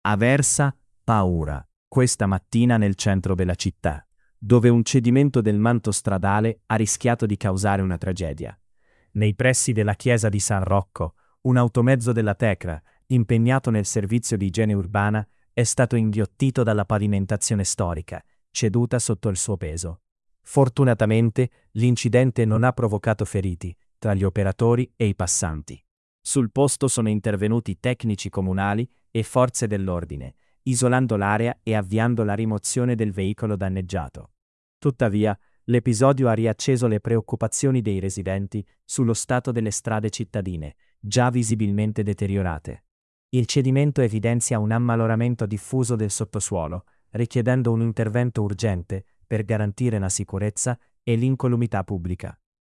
aversa-si-apre-una-voragine-davanti-al-carcere-sprofonda-un-camion-dei-rifiuti-tts-1.mp3